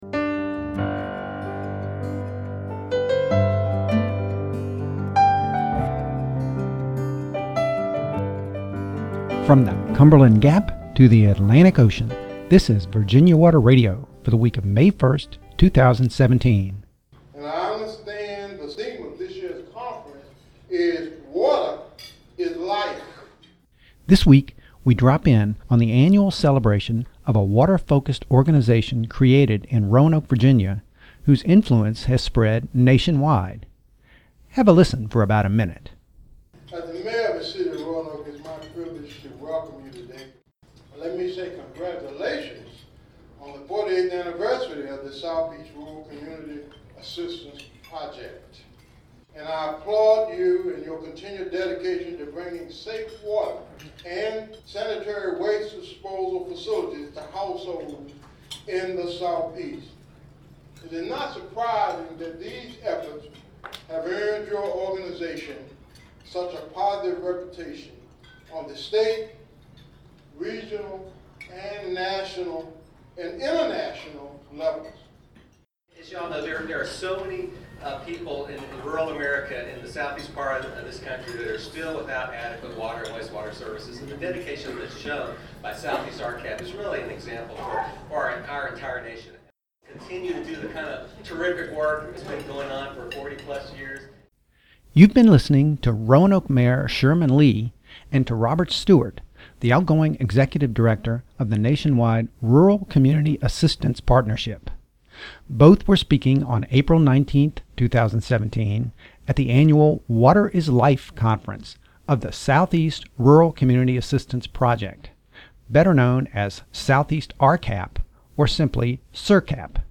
The guest voices heard in this episode were recorded by Virginia Water Radio at the “Water is Life” conference luncheon held by the Southeast Rural Community Assistance Project, or SERCAP, in Roanoke, Va., on April 19, 2017.